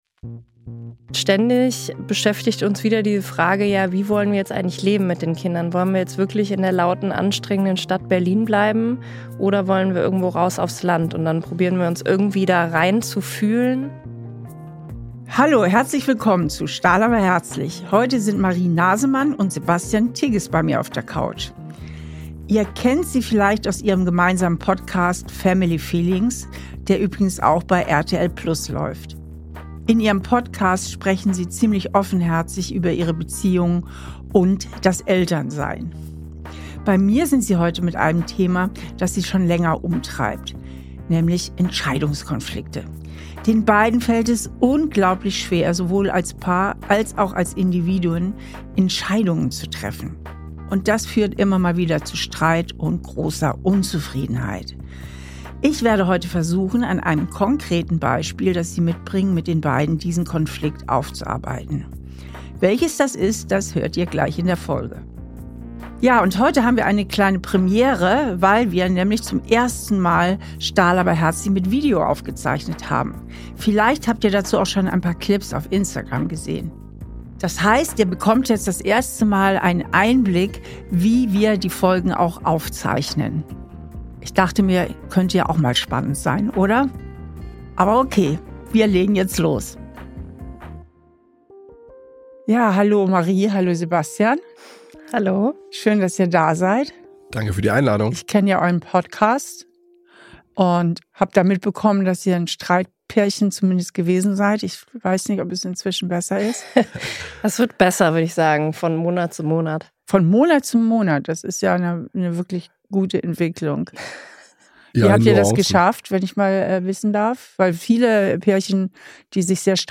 In dieser Folge habe ich das bekannte Podcaster-Paar auf der Therapiecouch.